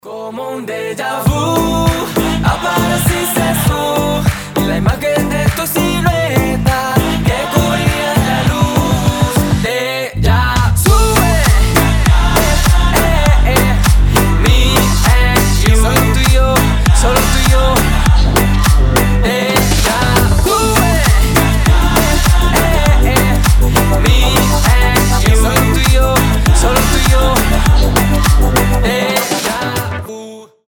• Качество: 320, Stereo
ритмичные
заводные
dancehall
Dance Pop
house